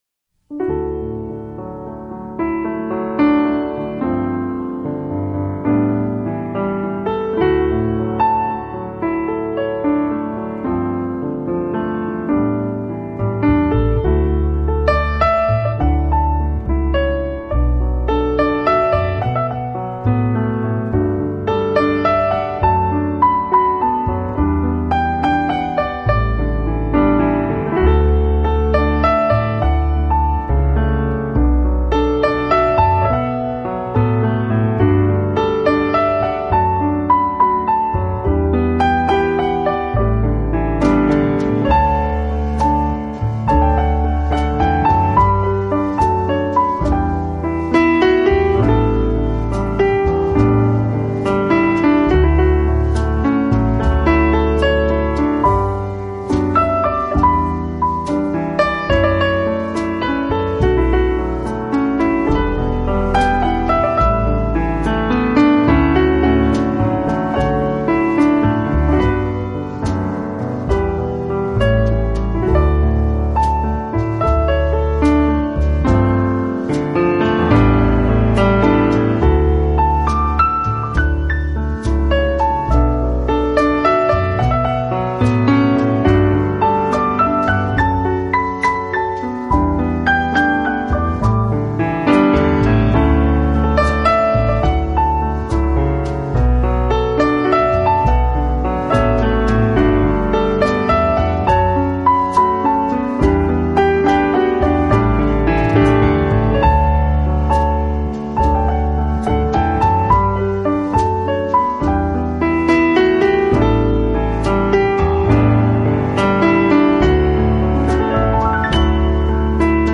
音乐类型：Instrumental 钢琴